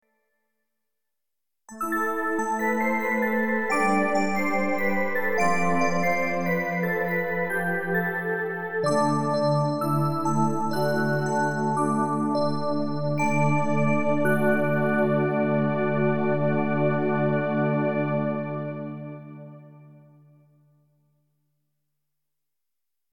Sachant que les sons que tu nous as fait écouter sont traité avec des effets style reverbe etc.
Mais il s'agit bel et bien de sons typiquement FM.
Rien que des sons FM très classiques et tout à fait reproductibles sur un DX7.